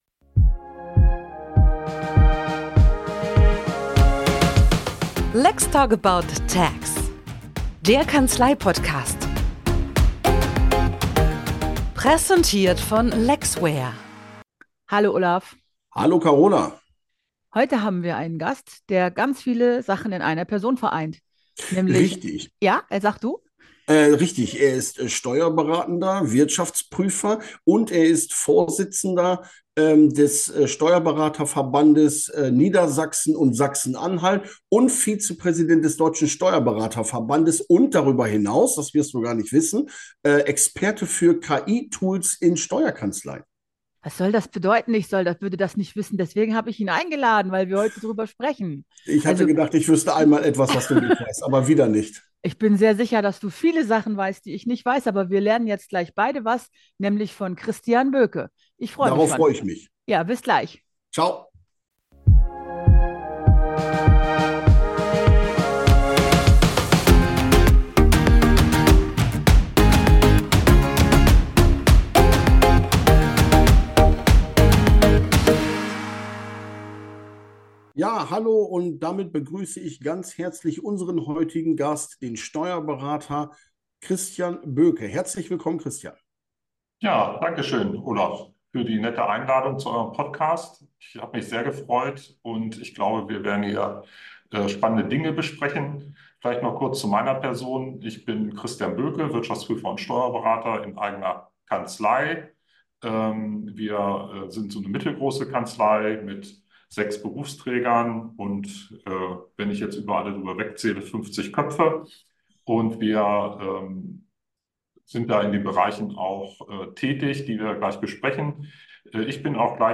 Dazu bringen sie nicht nur eigene Themen ein, sondern begrüßen auch regelmäßig Gäste aus der Branche, um mit ihnen in Dialog zu gehen und neue Perspektiven einzubringen.